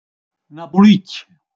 ascolta la pronunzia di Napulicch')